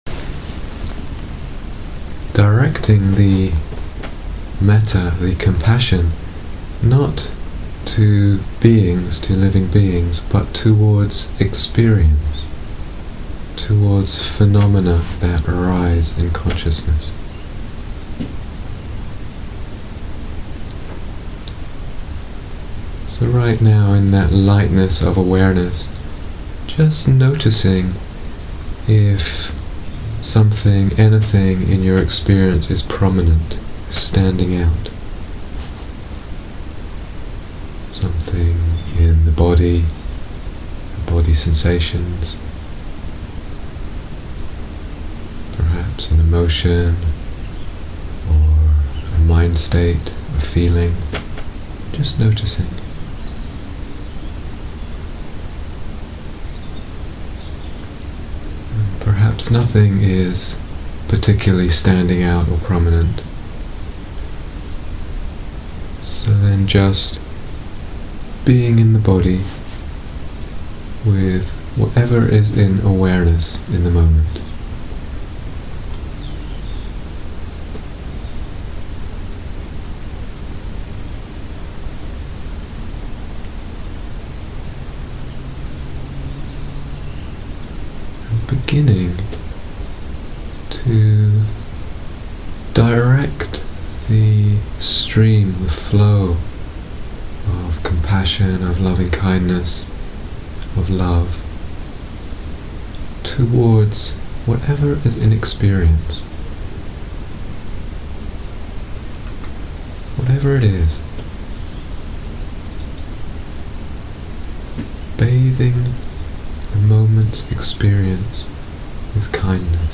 Guided Meditation - Mettā to Phenomena